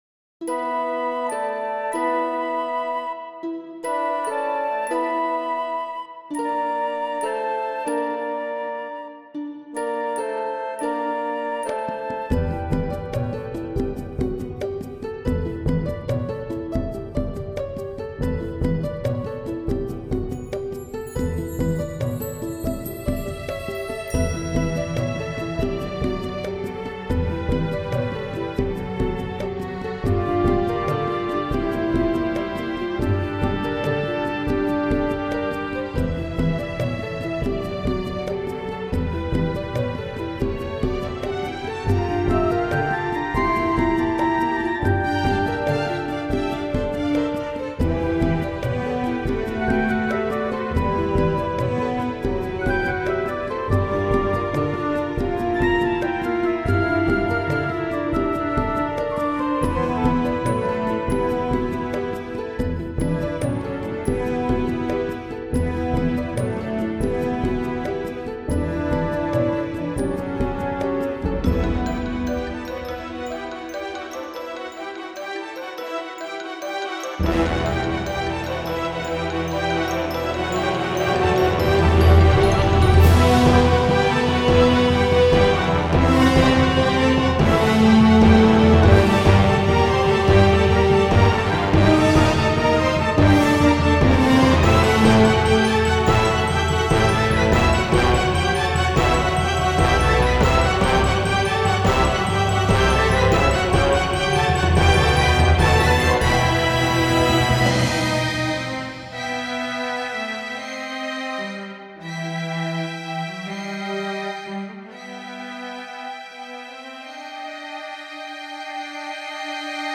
soundtrack/game music